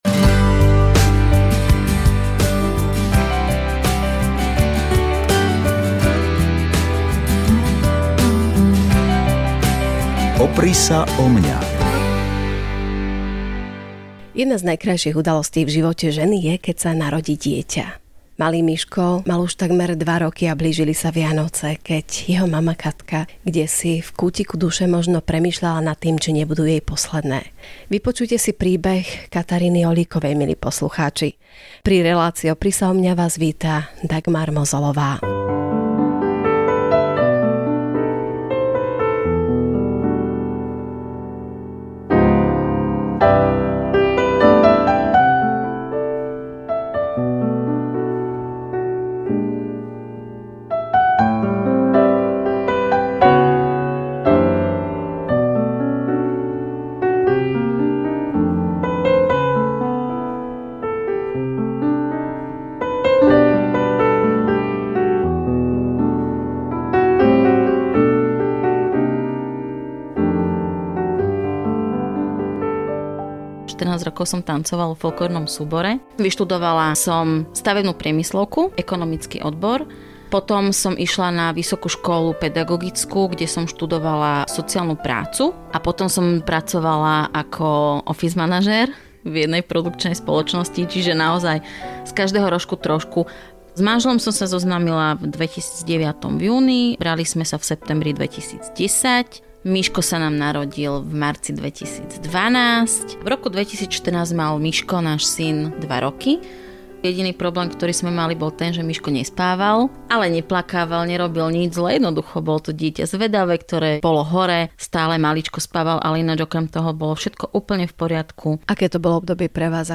Rozhovor odvysielaný v RTVS Relácii Opri sa o mňa, dňa 22.10.2017